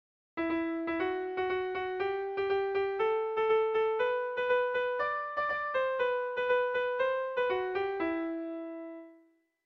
Irrizkoa
ABDE